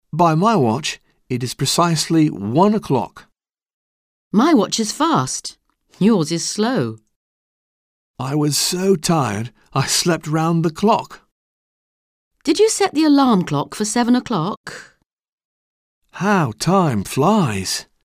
Un peu de conversation - La mesure du temps, dire l'heure